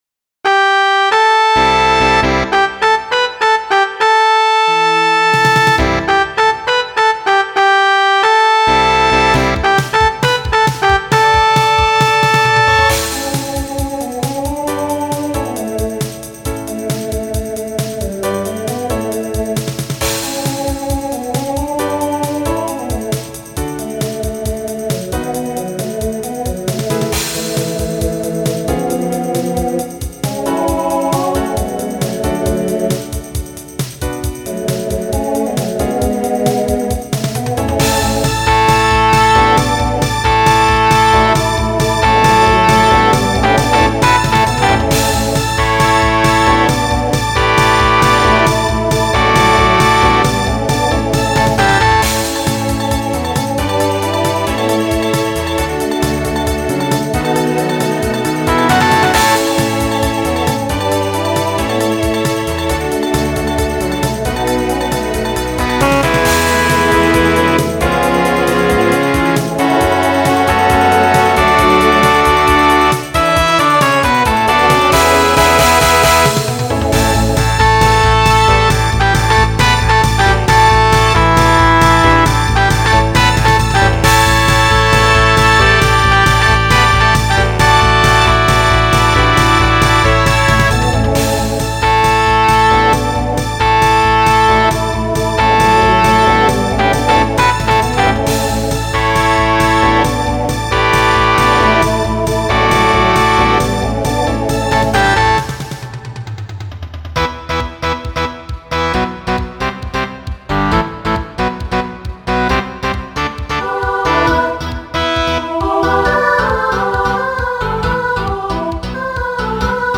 TTB/SSA
Voicing Mixed Instrumental combo Genre Pop/Dance